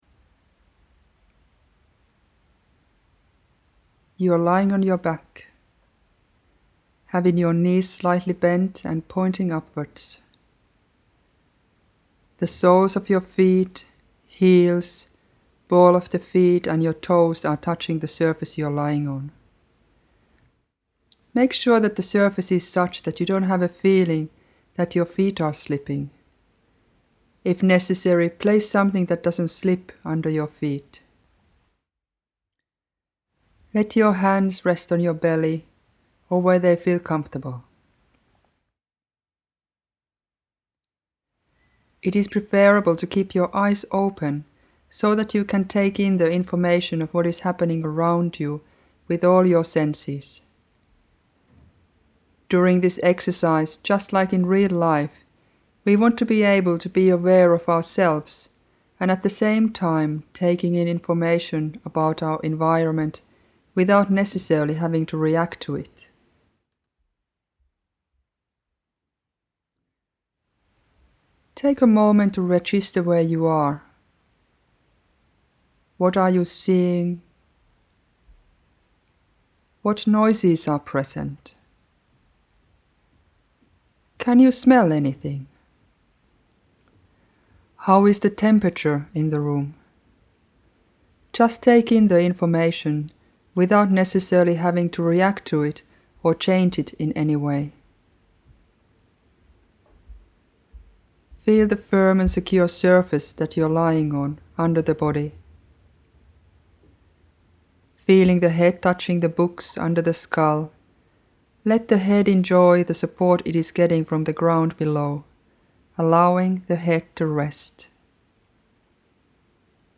This will make the exercise a bit like a guided meditation and at the same time you will get many more directions.